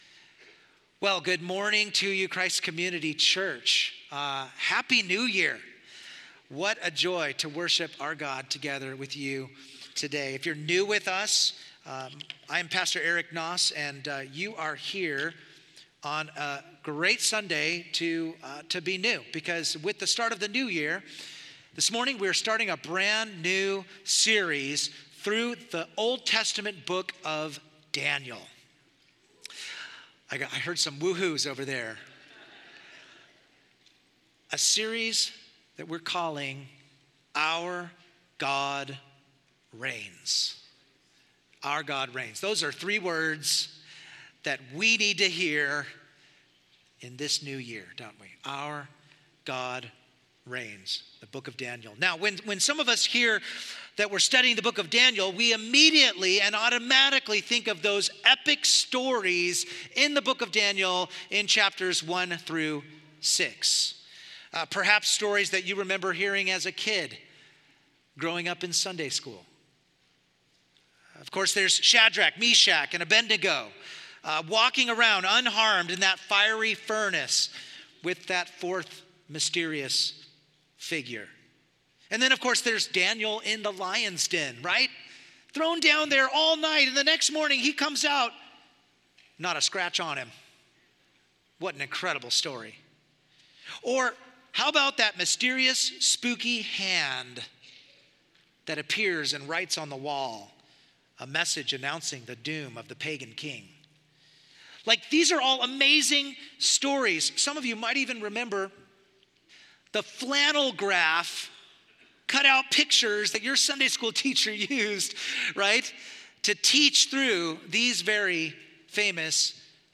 Sermons